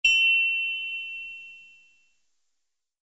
SA_writeoff_ding_only.ogg